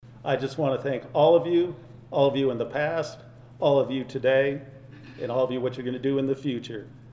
The Riley County Police Department held a 50th anniversary kickoff event Thursday at its headquarters.
Riley County Law Board Chairperson and city commissioner John Matta expressed his appreciation and thanks to the police department.